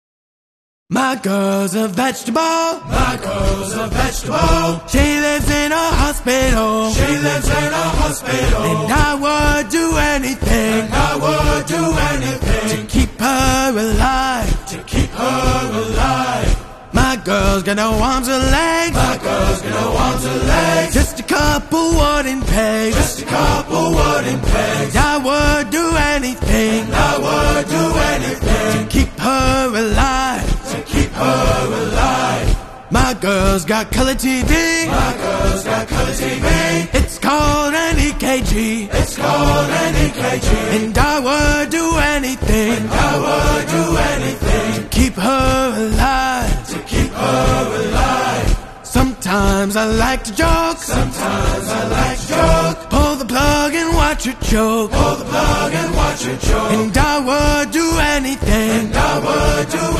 cadence in US military history